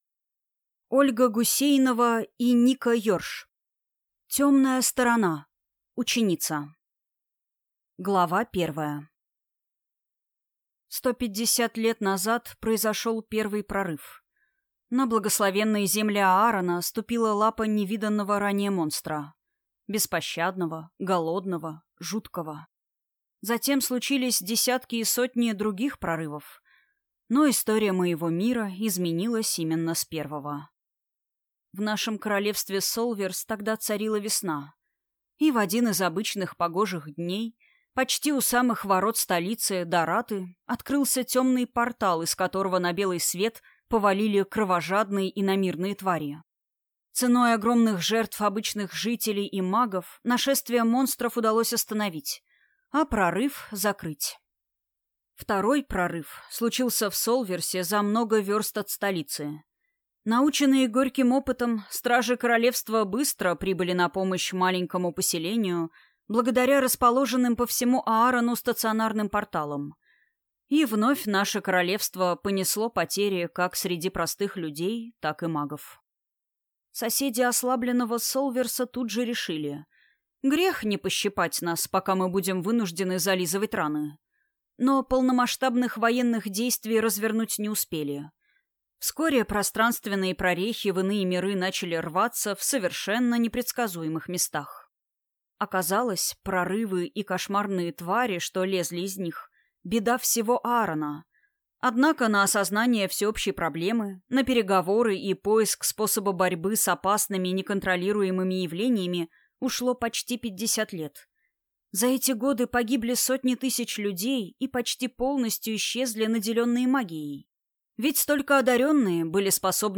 Аудиокнига Темная сторона. Ученица | Библиотека аудиокниг